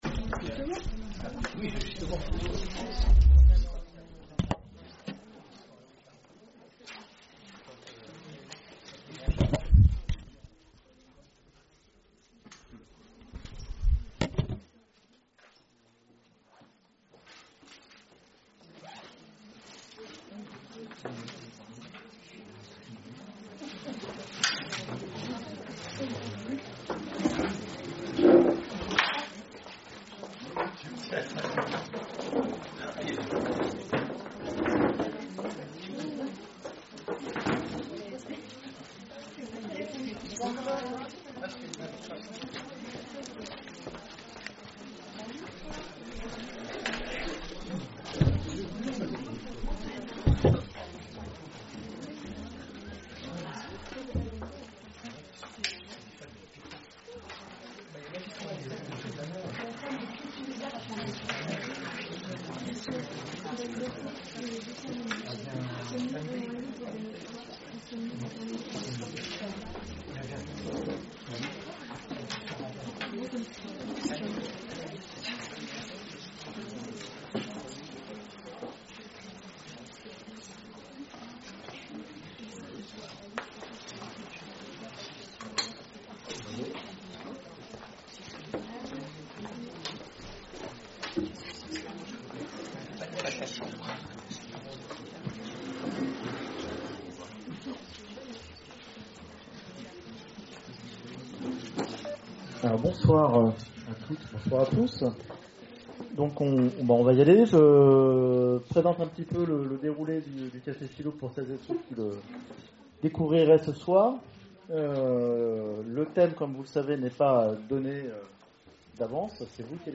Conférences et cafés-philo, Orléans
CAFÉ-PHILO